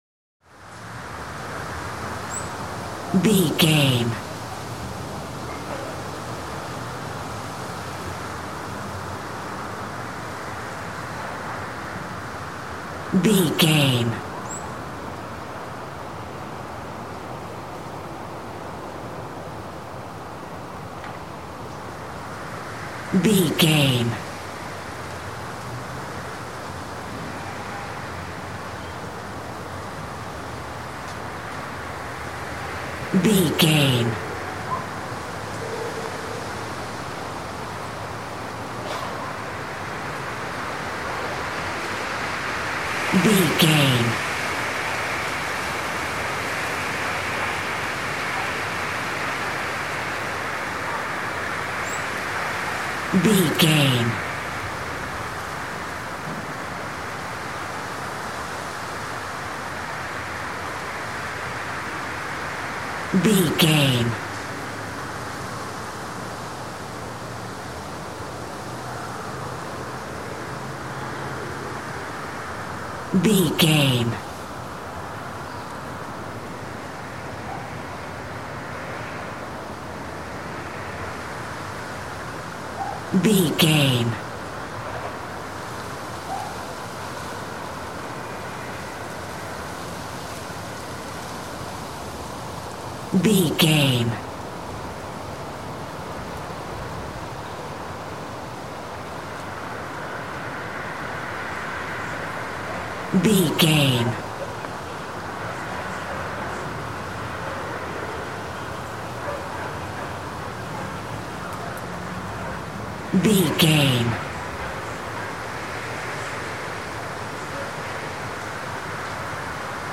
City rumble wind day
Sound Effects
chaotic
urban
ambience